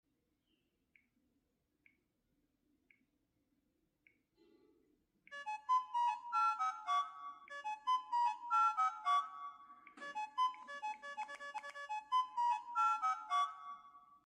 temu phones have this as their default notification sound 😭